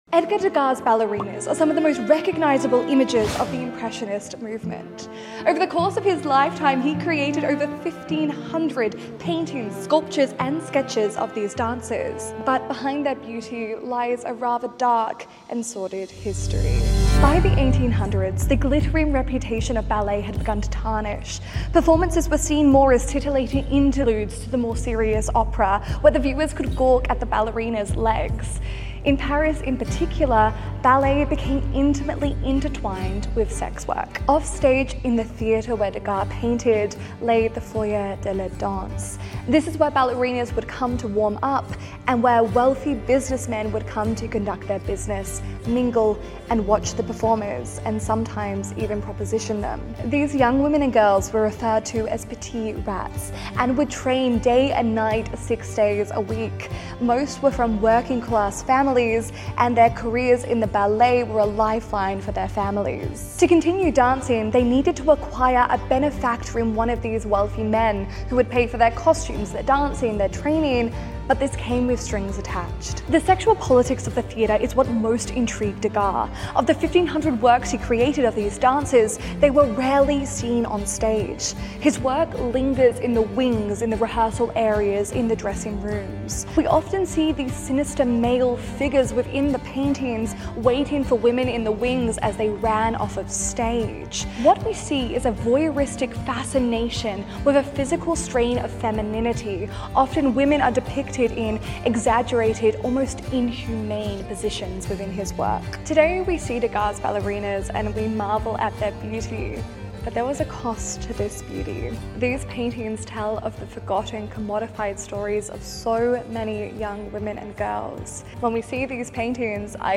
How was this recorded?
Filmed inside the incredible French Impressionism exhibition that is currently showing at the National Gallery of Victoria.